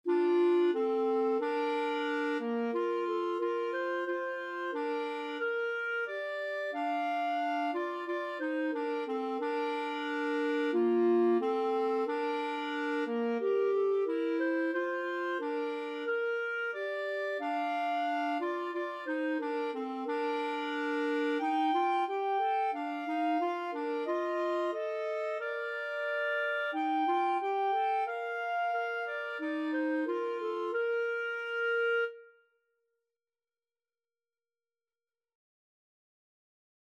Christmas Christmas Clarinet Duet Sheet Music Once in Royal Davids City
Free Sheet music for Clarinet Duet
4/4 (View more 4/4 Music)
Bb major (Sounding Pitch) C major (Clarinet in Bb) (View more Bb major Music for Clarinet Duet )
Clarinet Duet  (View more Easy Clarinet Duet Music)